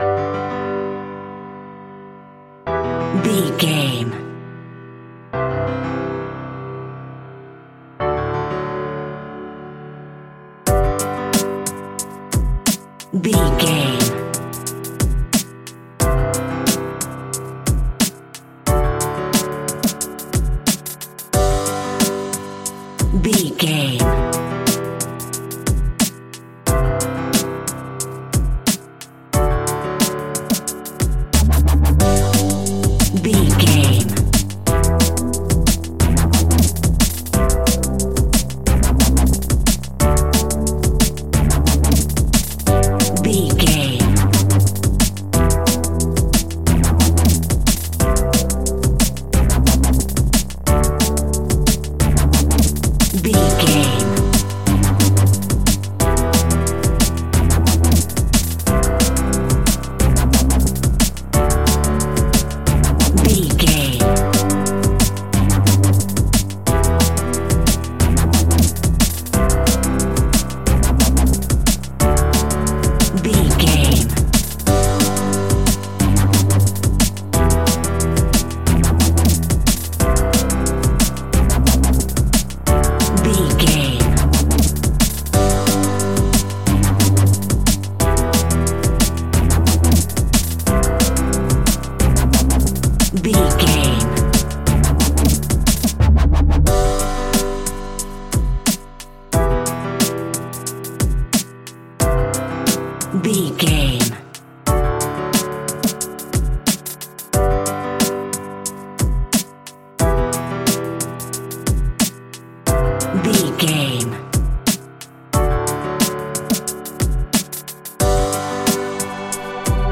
Ionian/Major
Fast
uplifting
lively
futuristic
hypnotic
industrial
frantic
drum machine
synthesiser
piano
electronic
sub bass
synth leads
synth bass